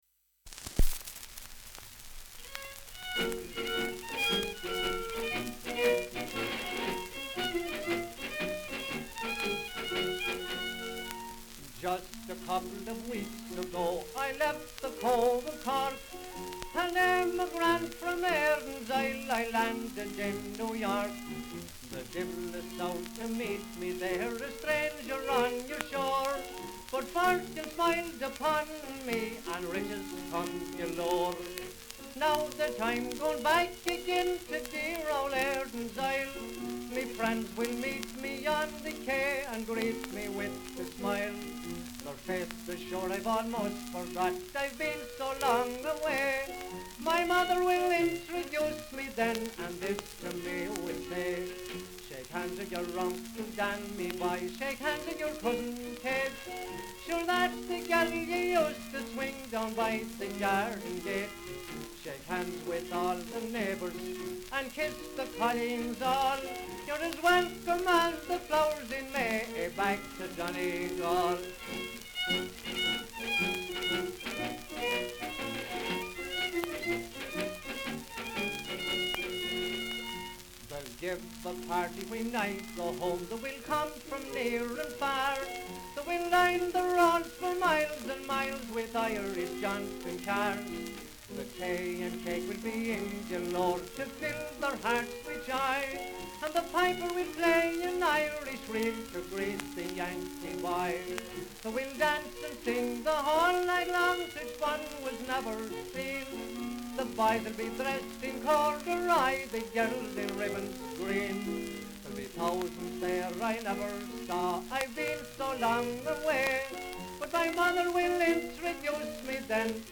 vocal, piano, fiddle, drum, clarinet?